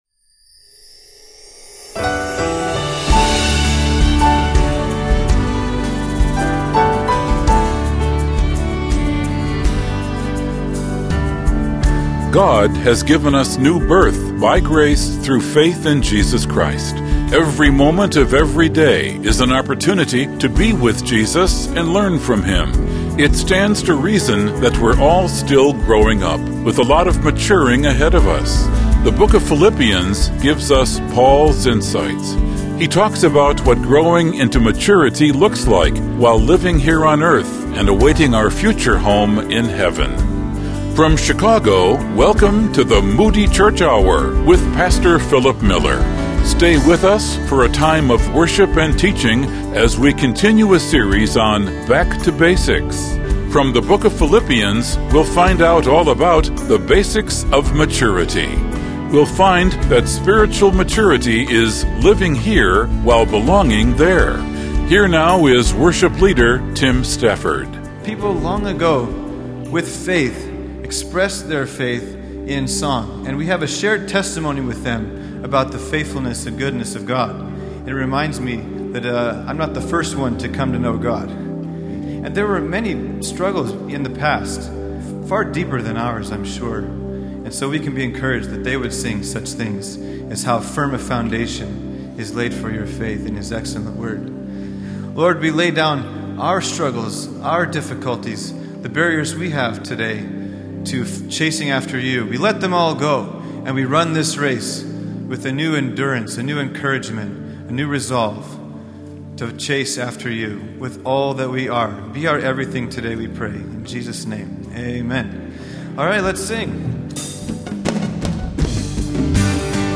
In this message from Philippians 2